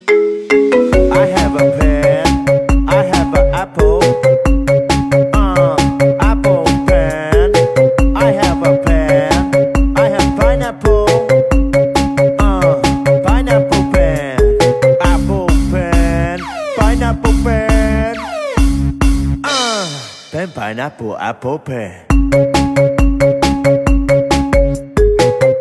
Kategorien: Marimba Remix